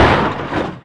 highway / oldcar / clip1.ogg